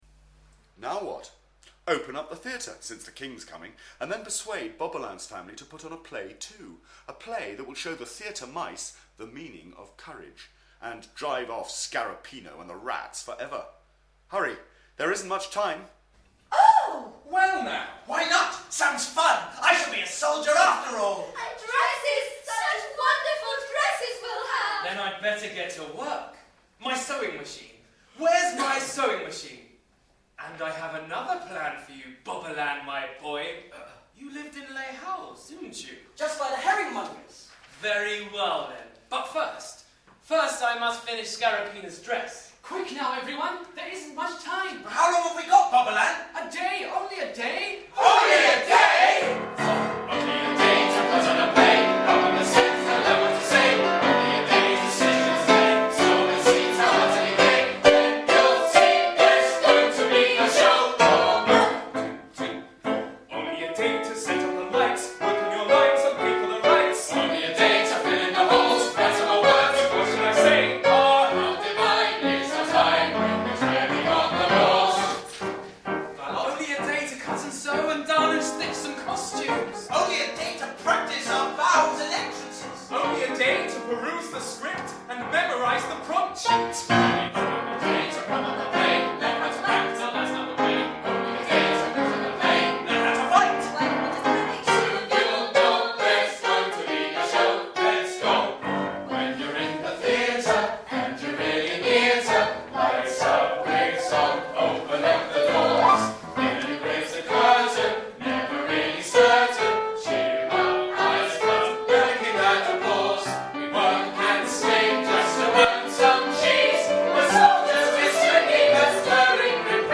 Mr Moliere’s Mouse, Royal Academy of Music workshop. Story, book and lyrics by David Clement-Davies, music by Michael Jeffrey.